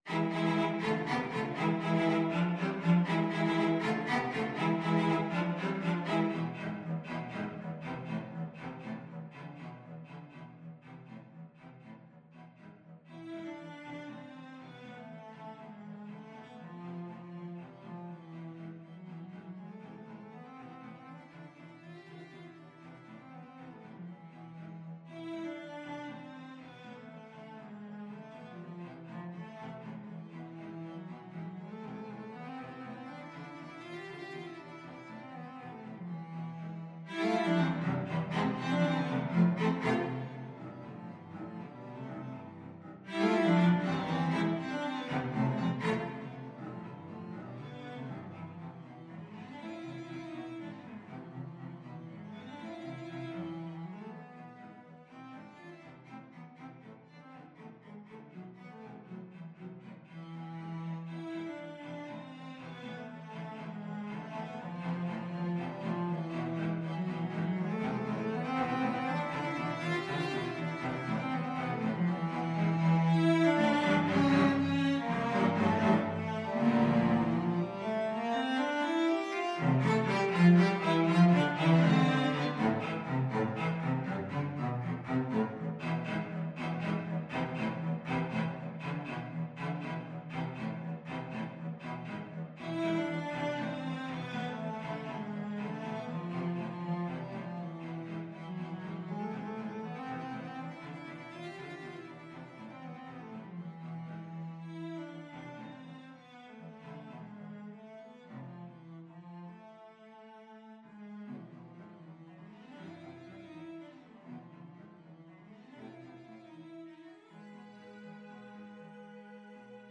Cello 1Cello 2Cello 3
3/8 (View more 3/8 Music)
Allegro vivo (.=80) (View more music marked Allegro)
Classical (View more Classical Cello Trio Music)